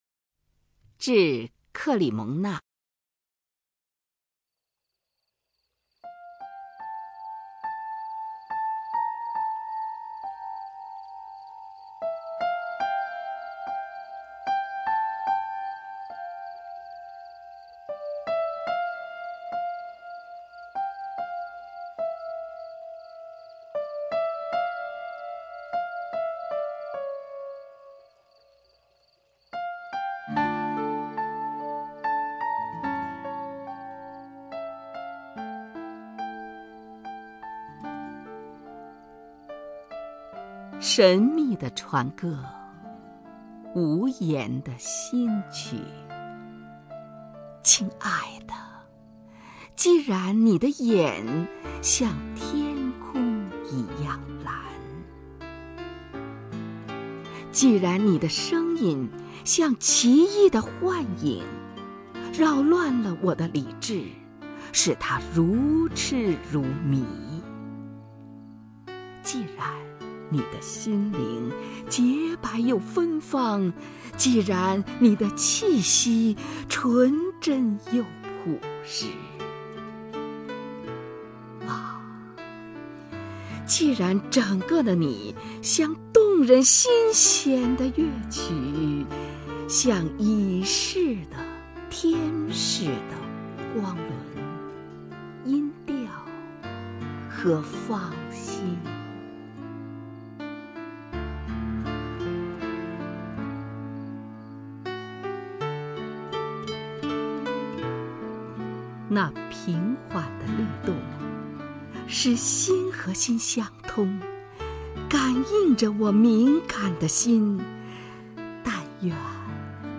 首页 视听 名家朗诵欣赏 虹云
虹云朗诵：《致克莉蒙纳》(（法）保尔·魏尔伦)